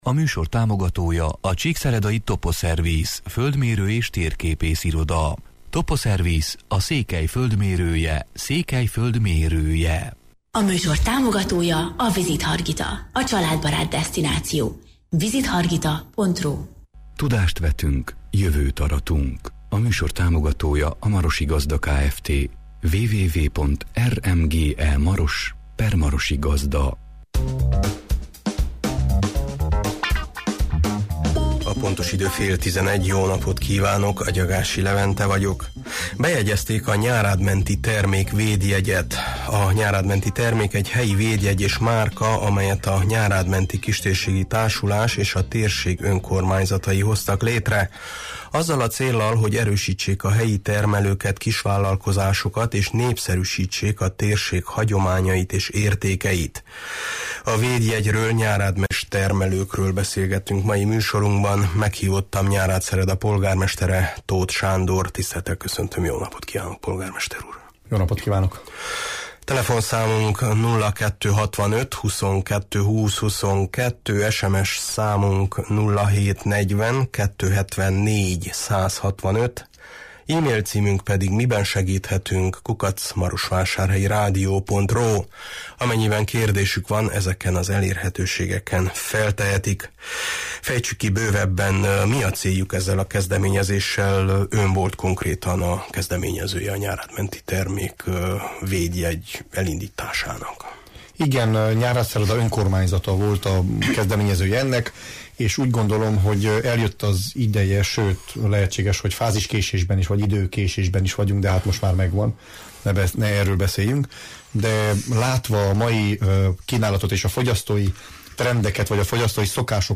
A Nyárádmenti Termék egy helyi védjegy és márka, amelyet a Nyárádmenti Kistérségi Társulás és a térség önkormányzatai hoztak létre azzal a céllal, hogy erősítsék a helyi termelőket, kisvállalkozásokat, és népszerűsítsék a térség hagyományait és értékeit. A védjegyről, nyárádmenti termékekről és termelőkről beszélgetünk mai műsorunkban.
Meghívottam Nyárádszereda polgármestere Tóth Sándor: